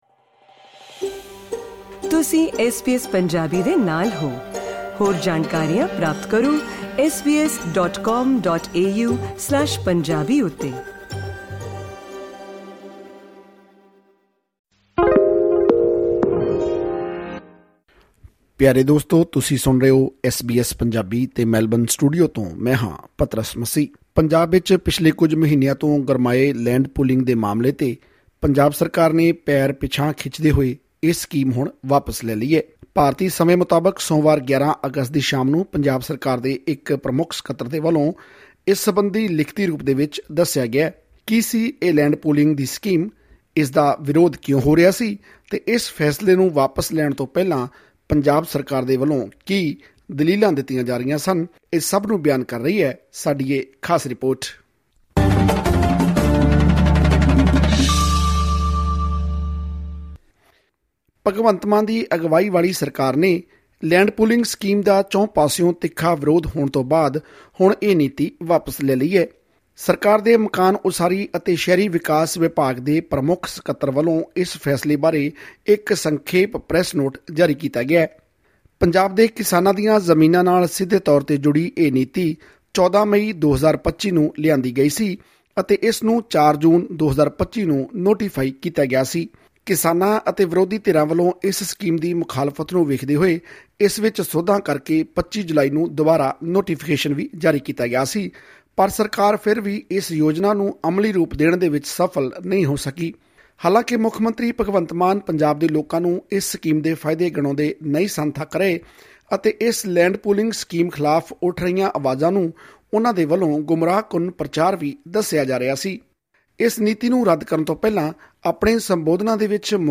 ਇਸ ਸਕੀਮ ਬਾਰੇ ਅਤੇ ਪੂਰੇ ਘਟਨਾਕ੍ਰਮ ਸਬੰਧੀ ਹੋਰ ਜਾਣਕਾਰੀ ਲਈ ਸੁਣੋ ਇਹ ਆਡੀਓ ਰਿਪੋਰਟ...